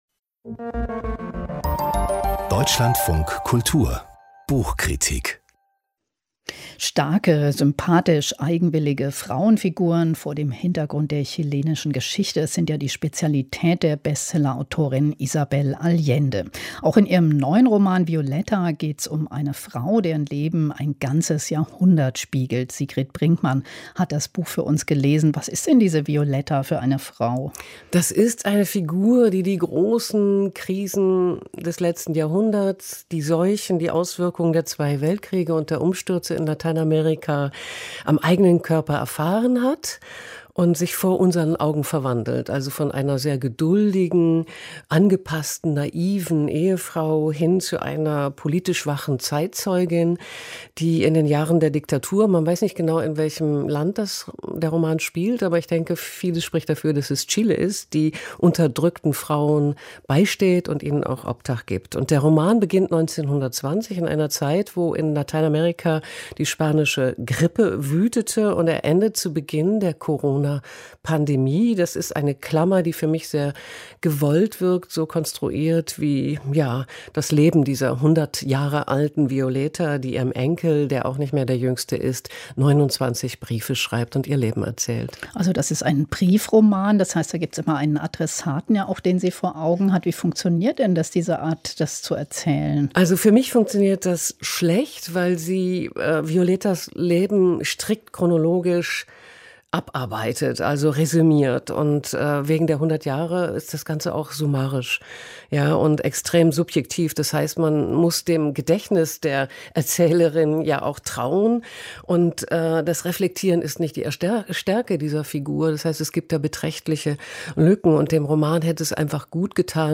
Buchkritik: "Violeta" von Isabel Allende